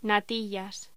Locución: Natillas
voz